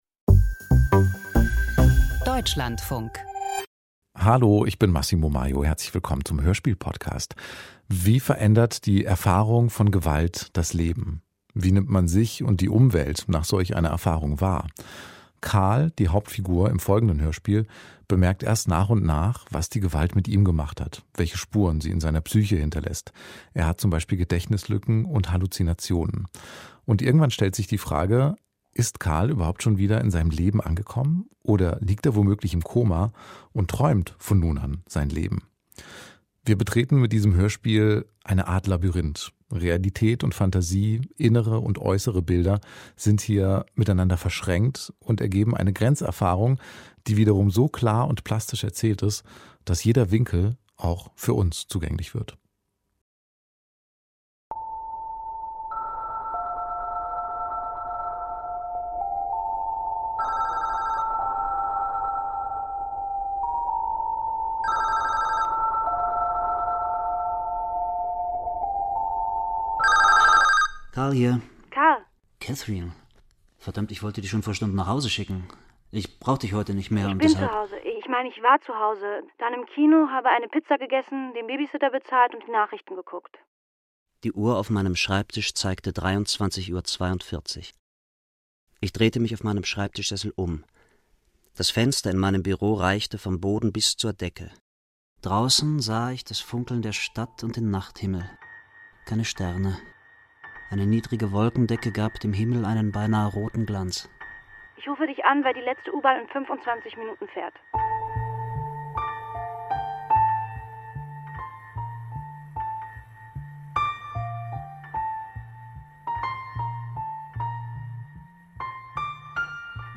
Hörspiel Archiv Im Labyrinth zwischen Traum und Wirklichkeit Das Koma 50:55 Minuten Wo geht's denn nur aus diesem Traum raus?